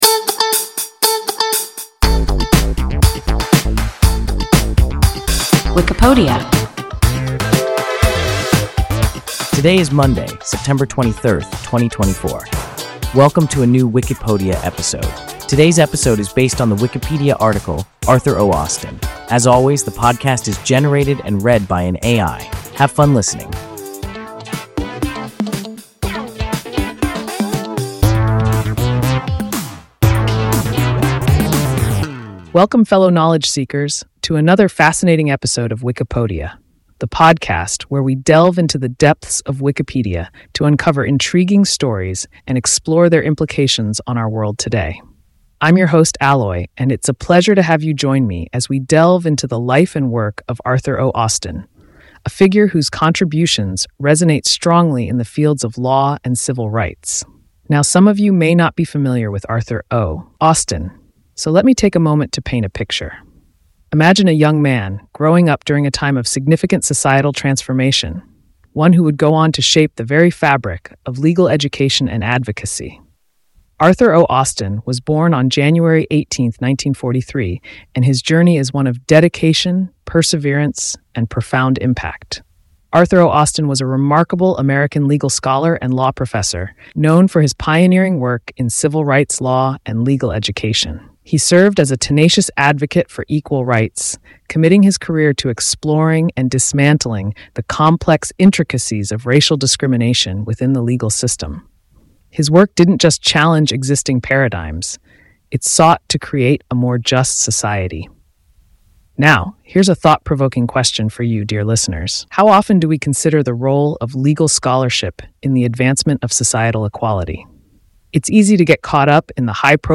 Arthur O. Austin – WIKIPODIA – ein KI Podcast